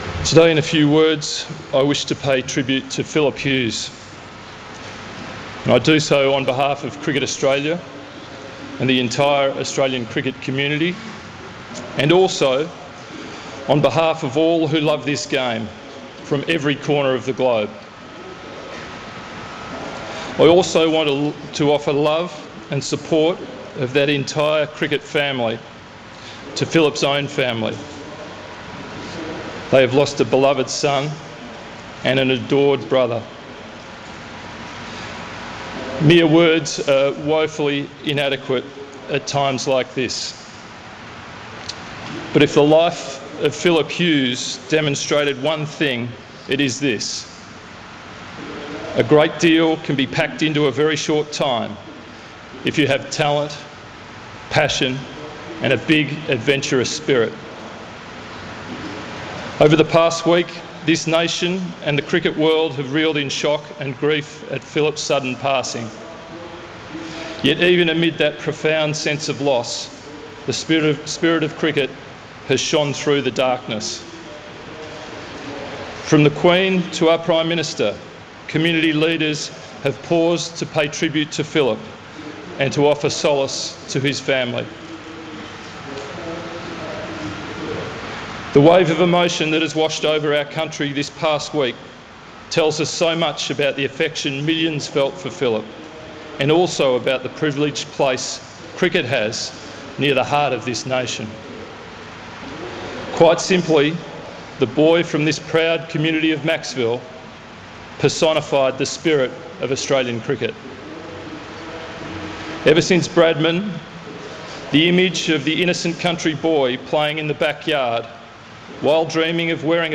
The CEO of Cricket Australia, James Sutherland, delivers his speech and well wishes to the congregation.